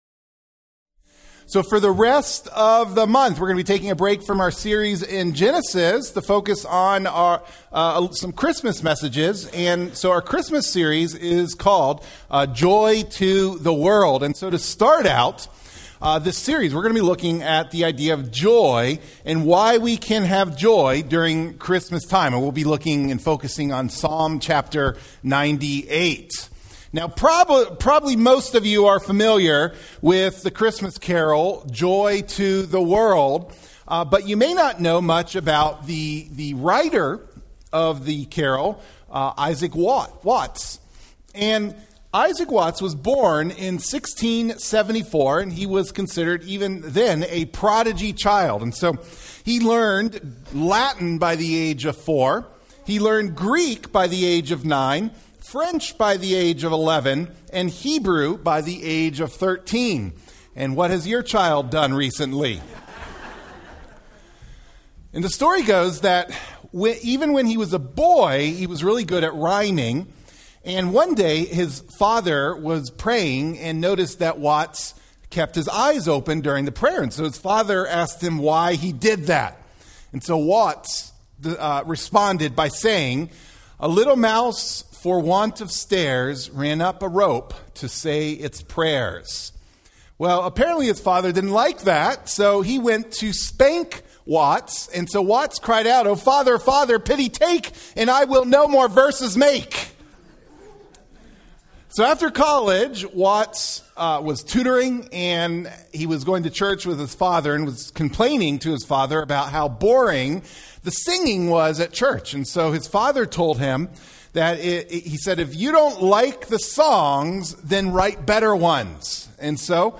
Sermon from Island Pond Baptist Church, Church in Hampstead, NH.